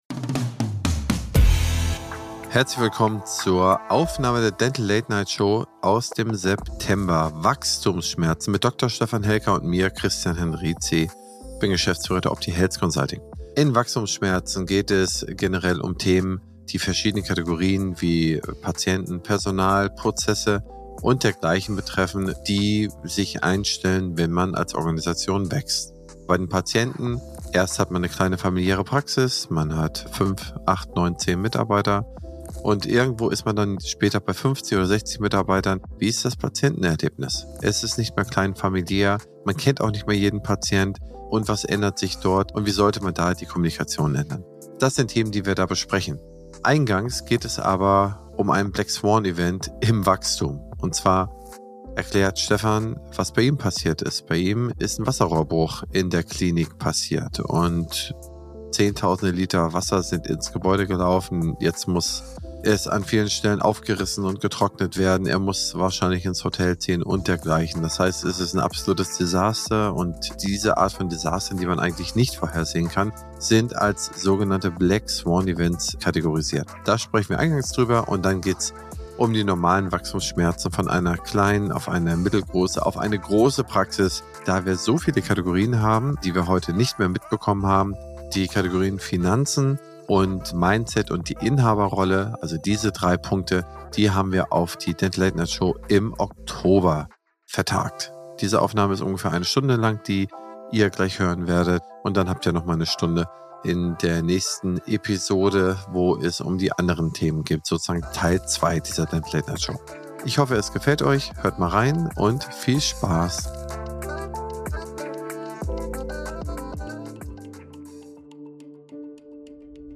In dieser spannenden Episode, einer Aufzeichnung der Dental Late Night Show